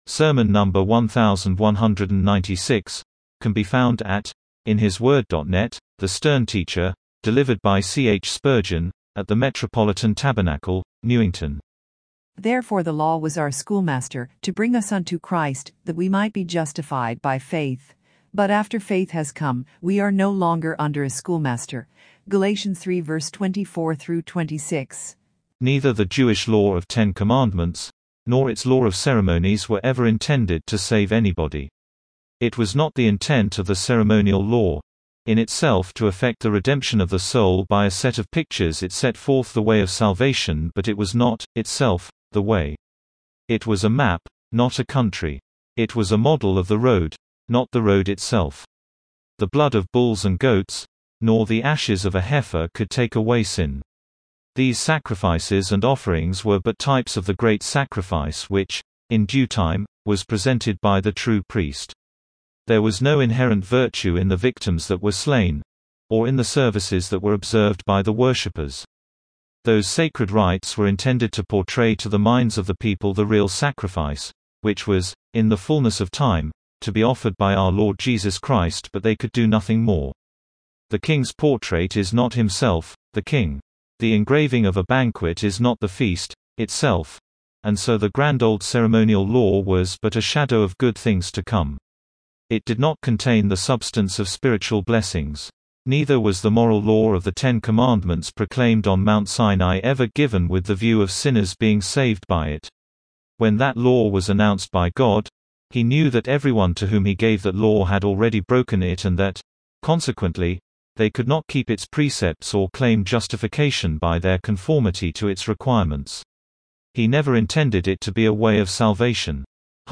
A SERMON DELIVERED ON LORD’S-DAY MORNING, NOVEMBER 8, 1874, BY C. H. SPURGEON,